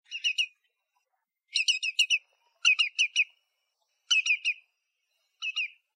Tringa flavipes
Yellowlegs, Lesser
Yellowlegs_Lesser.oga